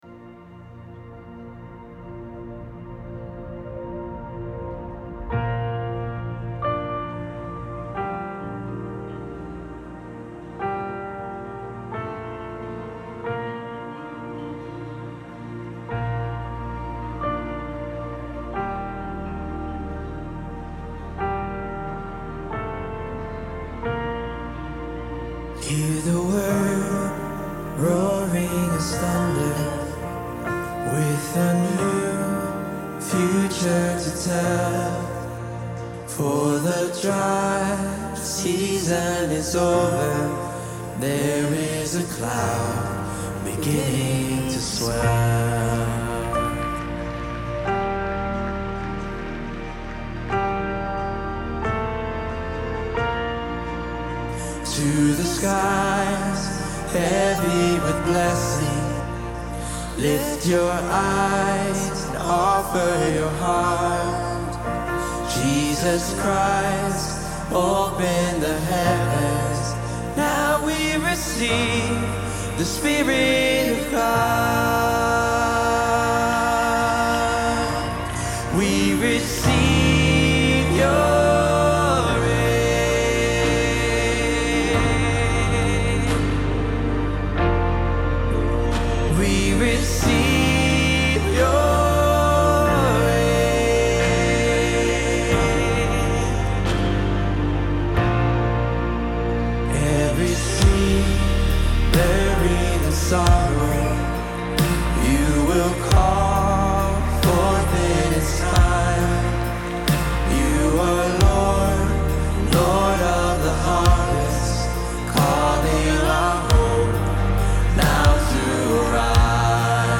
Worship Playlist Listen to music recording LIVE during our Services